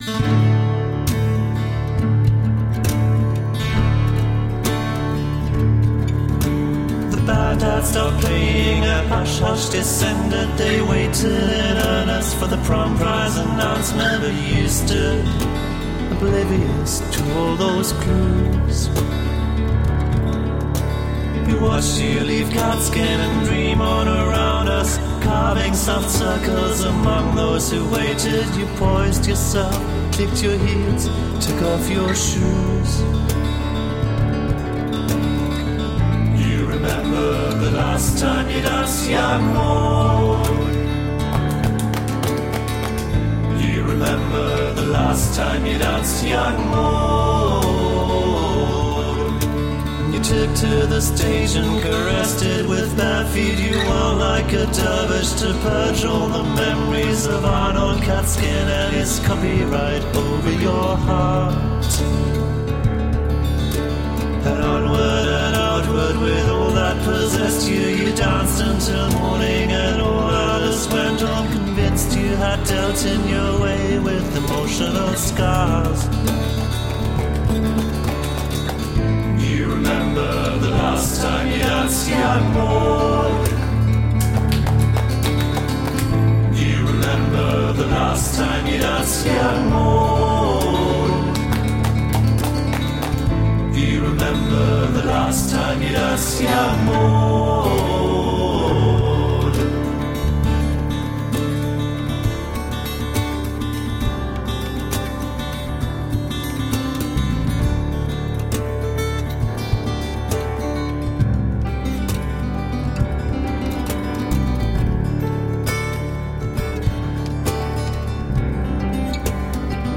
Tagged as: Alt Rock, Folk-Rock